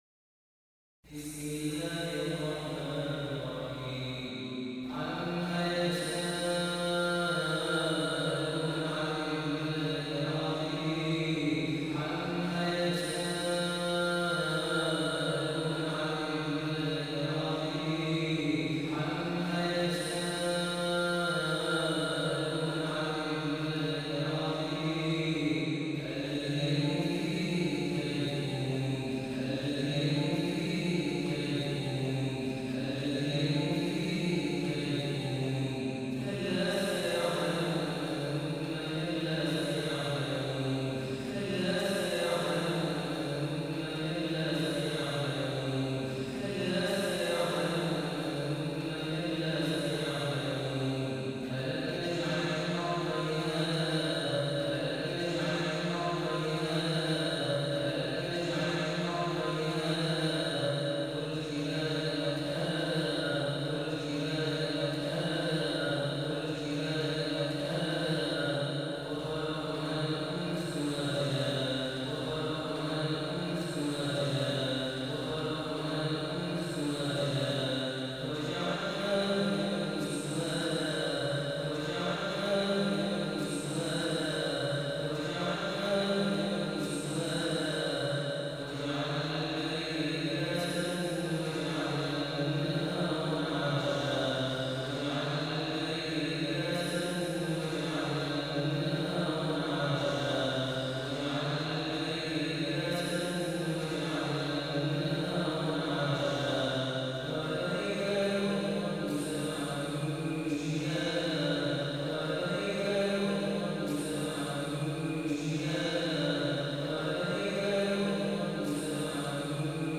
The Holy Quran recitation for Famous readers to listen and download